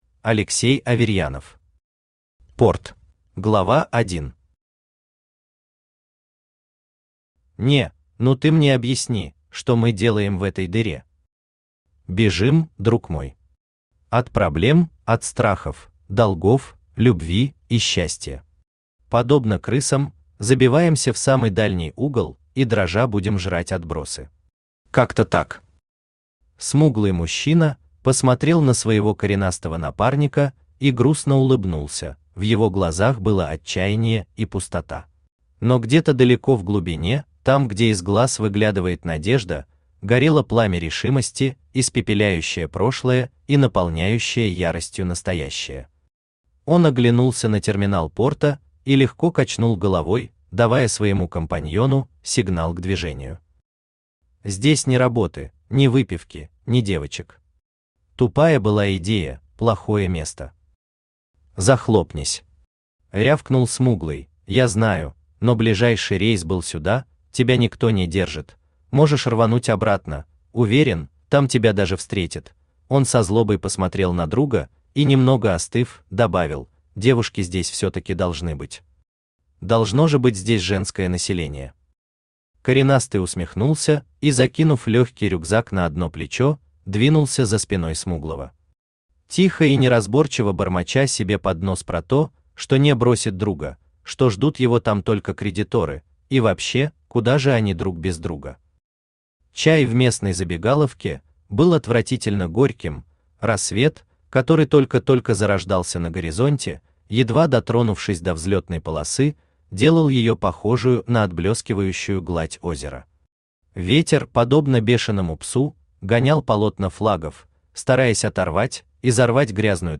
Aудиокнига Порт Автор Алексей Аверьянов Читает аудиокнигу Авточтец ЛитРес.